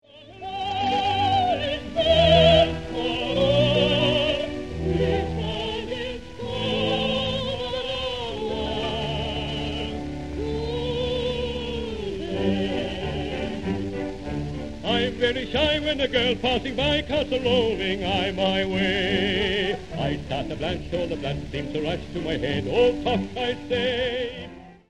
Light opera